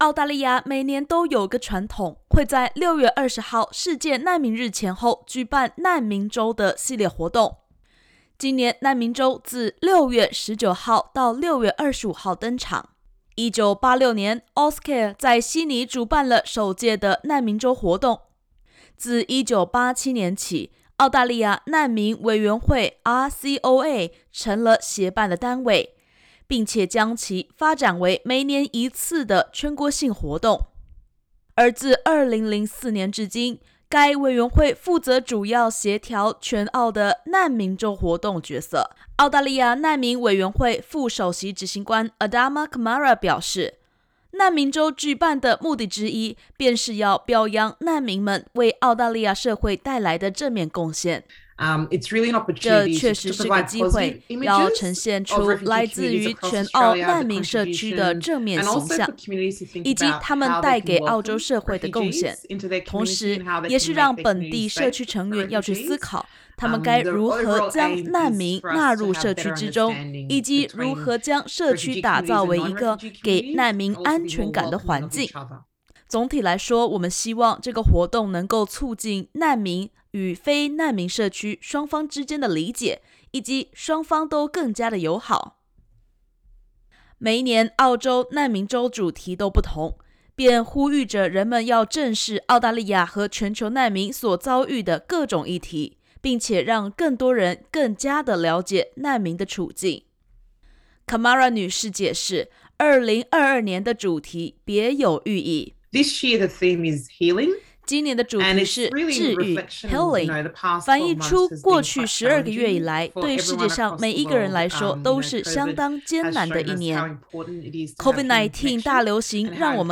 （點擊首圖收聽完整寀訪音頻）